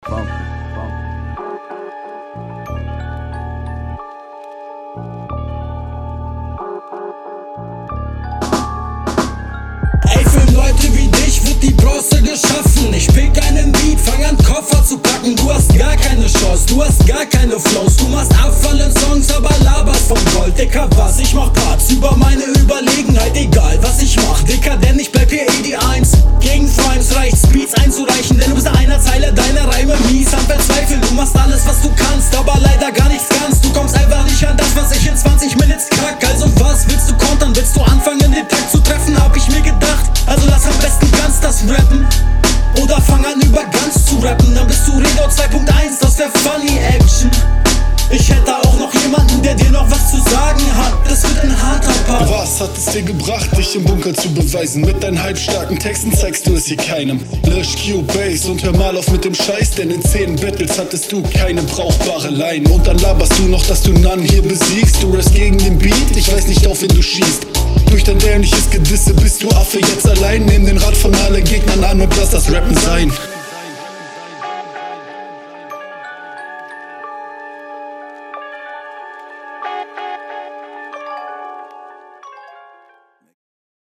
doper beat
Der Beat passt krass zu dir!
Kopfnickershit.